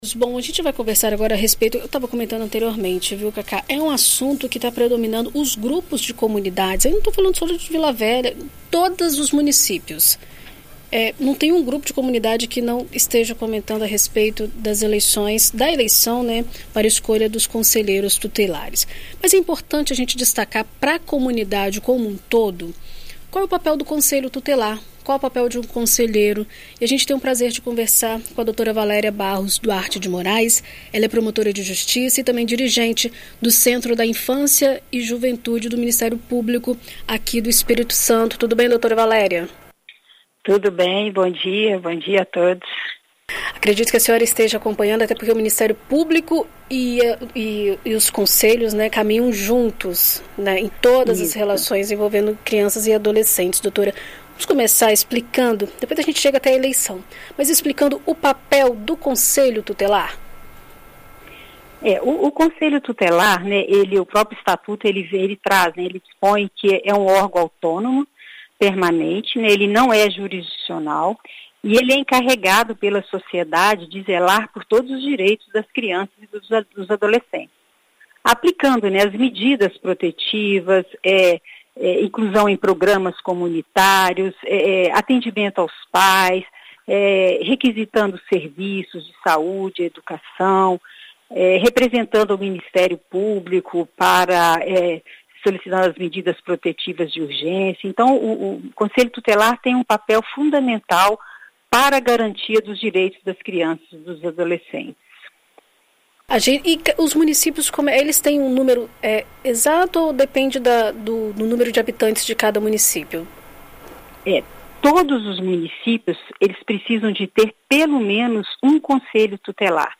Em entrevista à BandNews FM Espírito Santo nesta quinta-feira (21), a promotora de Justiça e dirigente do Centro da Infância e Juventude (CAIJ) do Ministério Público do Estado do Espírito Santo (MPES), Valeria Barros Duarte de Morais, esclarece todas as dúvidas sobre o tema.